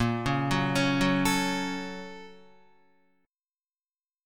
BbmM7#5 chord